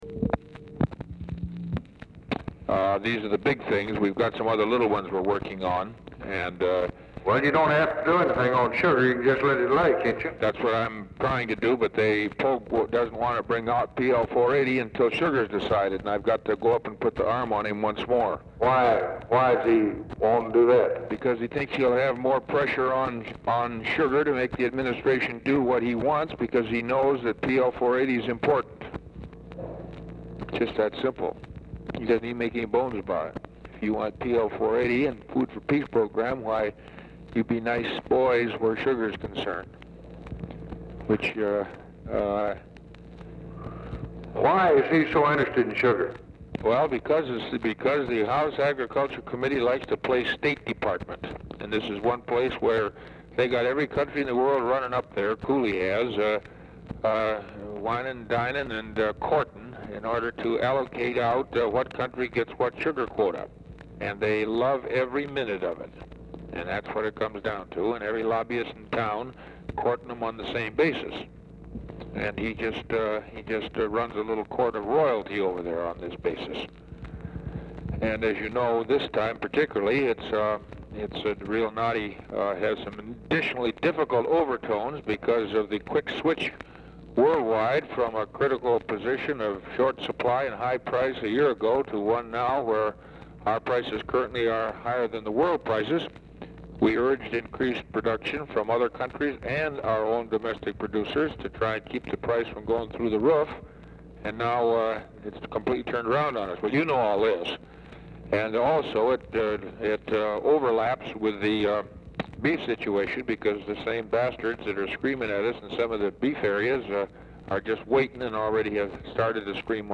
Telephone conversation # 3959, sound recording, LBJ and ORVILLE FREEMAN
Format Dictation belt
Location Of Speaker 1 Oval Office or unknown location